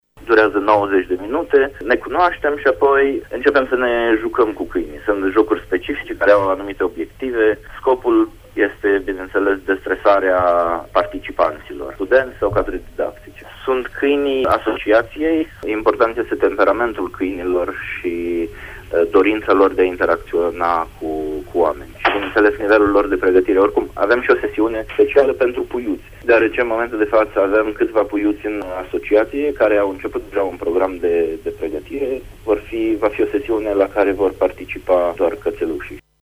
extras emis. Pulsul Zilei